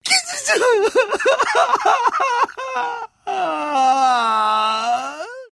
На этой странице собрана коллекция звуков, сопровождающих мелкие проблемы и досадные недоразумения.
Реакция мужчины на проблему